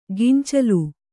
♪ gincalu